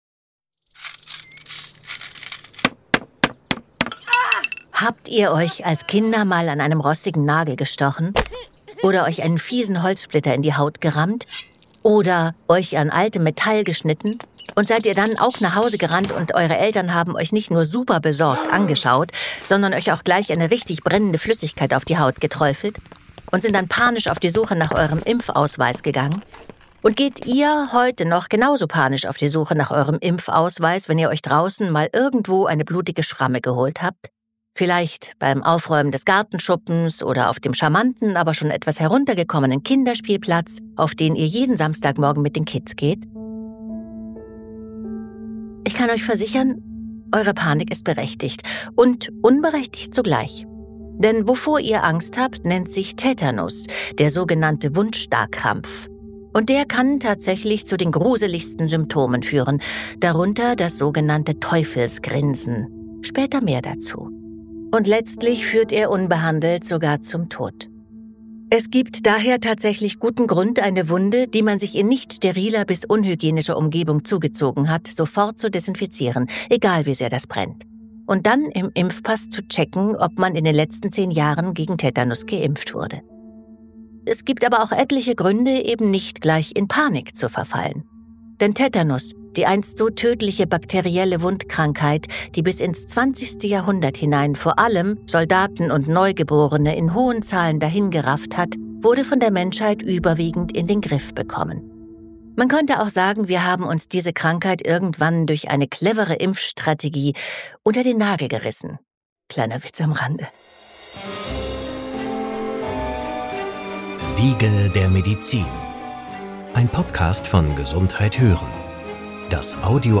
Vom qualvollen Tod zum Sieg der Medizin: Andrea Sawatzki erzählt die spannende Geschichte des Tetanus.